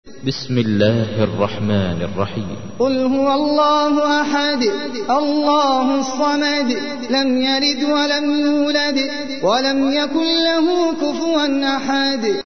تحميل : 112. سورة الإخلاص / القارئ احمد العجمي / القرآن الكريم / موقع يا حسين